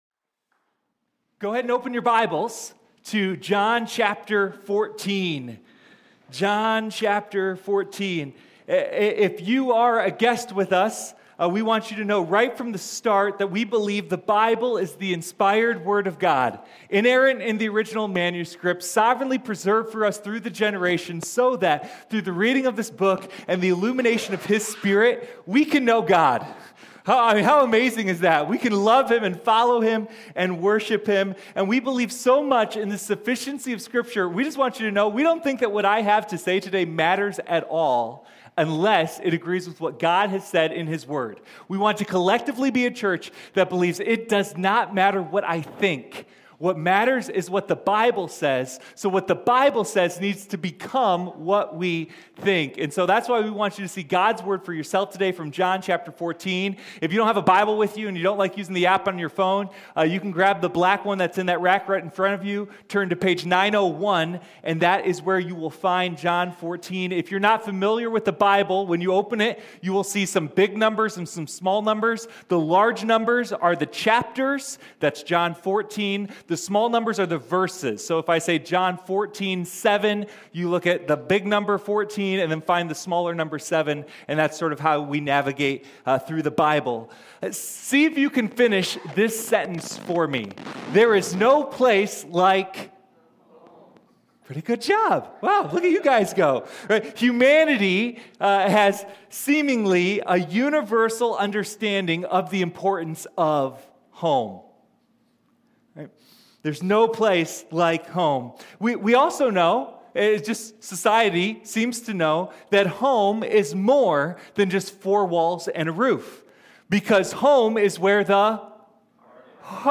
I AM: the Way, the Truth, and the Life – First Baptist Church